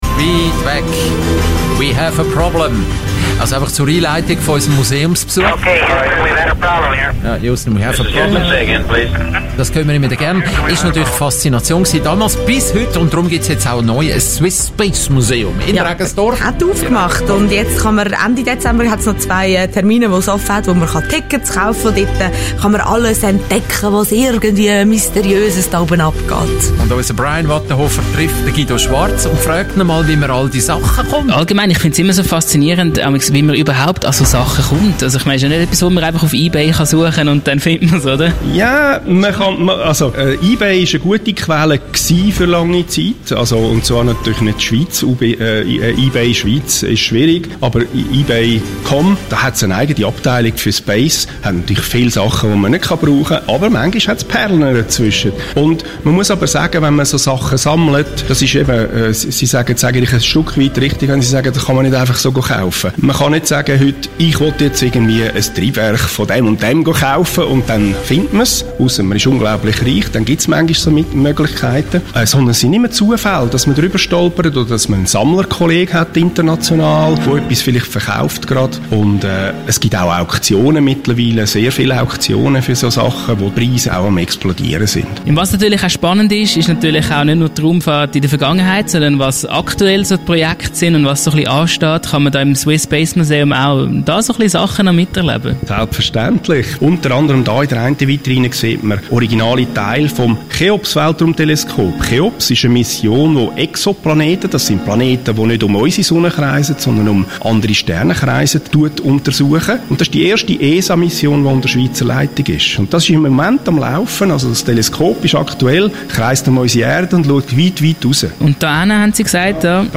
Radiobeitrag Teil 3